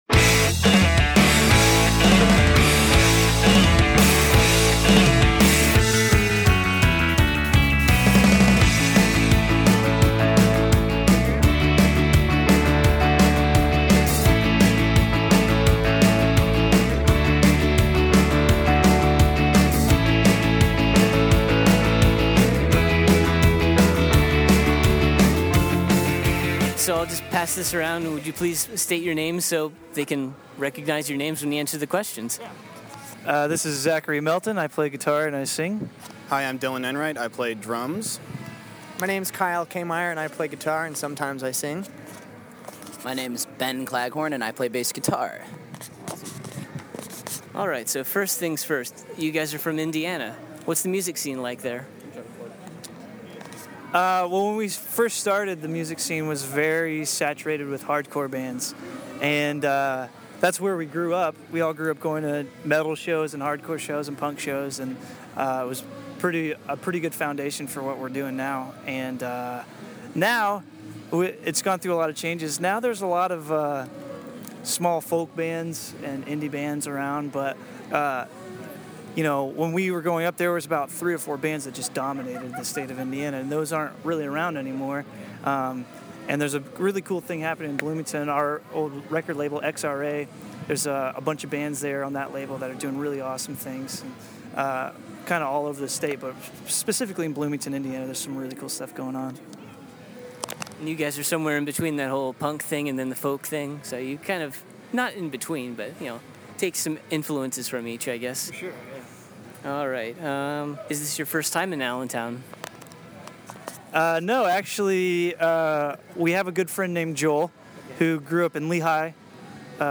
Exclusive: Rodeo Ruby Love Interview
08-interview-rodeo-ruby-love.mp3